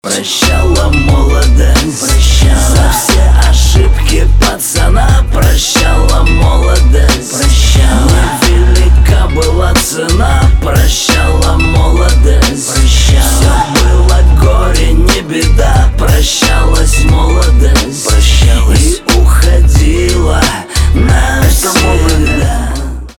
русский рэп
грустные
гитара , басы